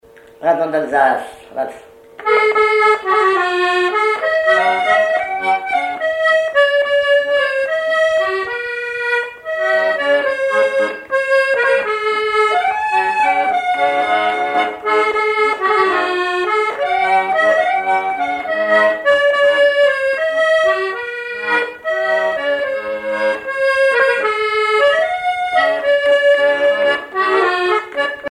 accordéon(s), accordéoniste
danse : valse musette
Pièce musicale inédite